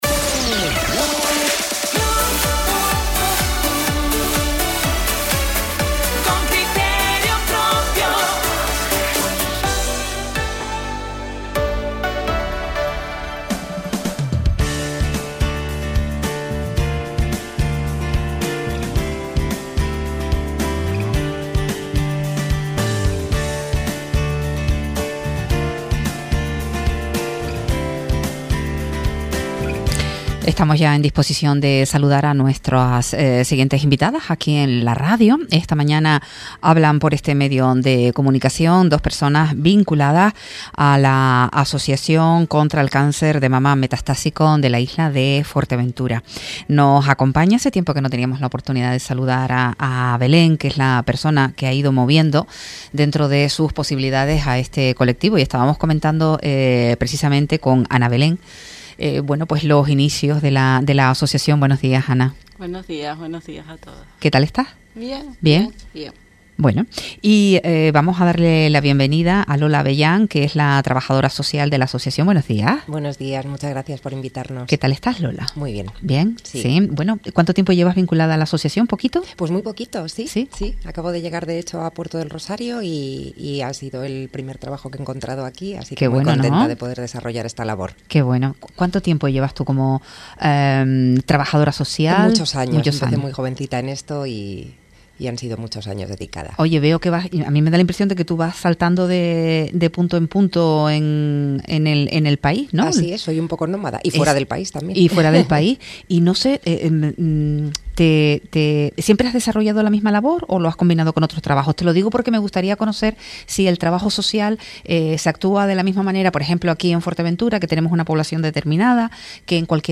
Entrevistas Convenio de colaboración entre el ayuntamiento de Puerto del Rosario y la Asociación Contra el Cáncer de Mama Metastásico Fuerteventura.